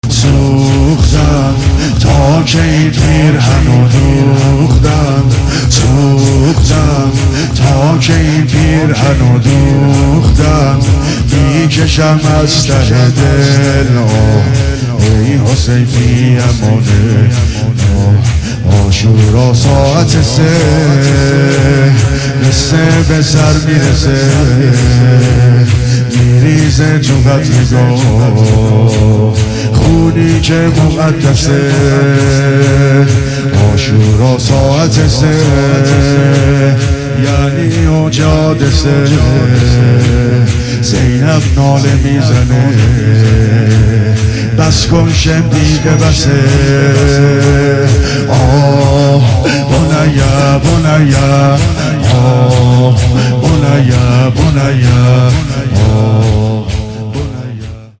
مداحی
محرم 1400 | حسینیه کربلا تهران